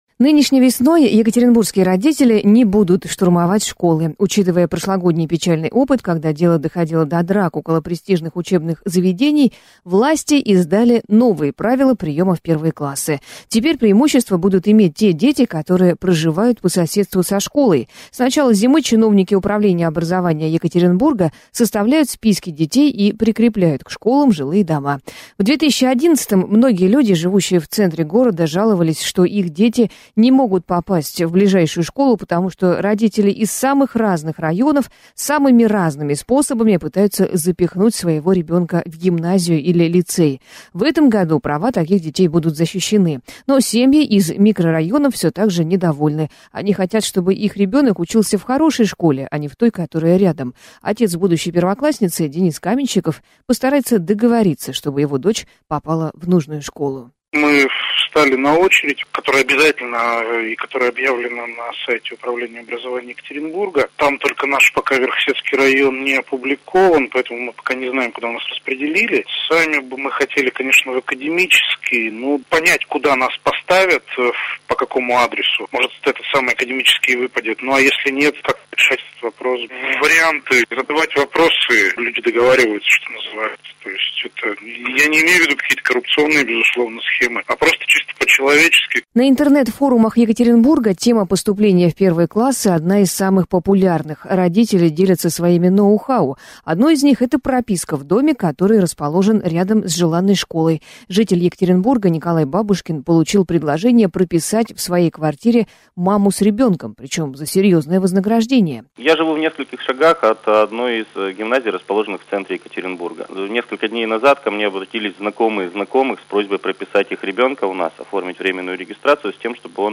Как попасть в престижную школу - репортаж